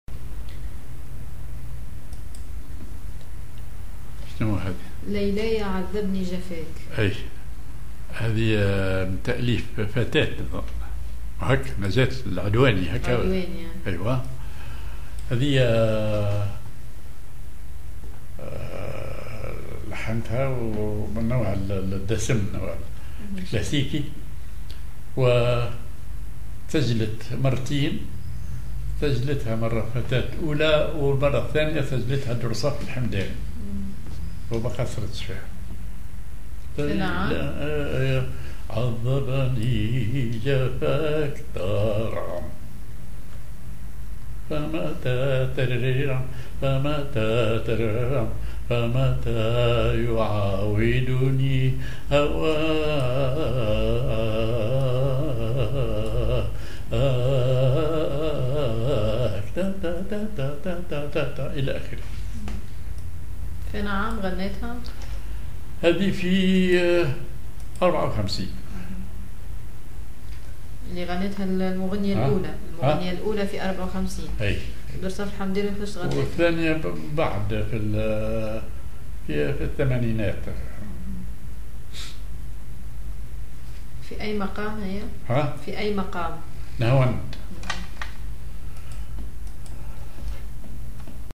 ar نهاوند
ar وحدة
أغنية